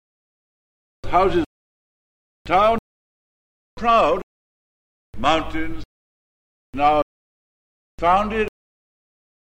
All the recordings have been subjected to lossy MP3 compression at some time during their lives.
Listen to the [au-aɔ]-like MOUTH by Harold MacMillan (Figure 5),
ordered by rising final F1 from [au] to [aɔ]: